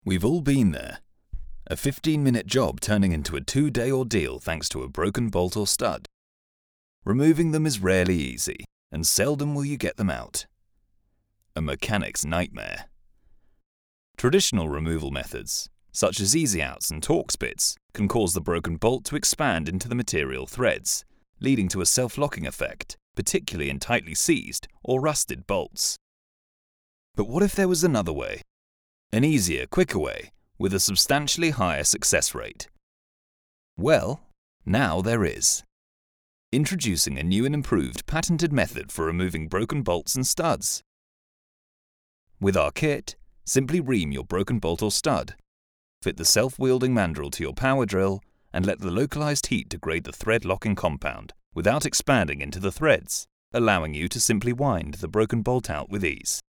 Vídeos explicativos
Mi voz cálida y tranquilizadora puede darle vida a cualquier guion, proyecto o resumen.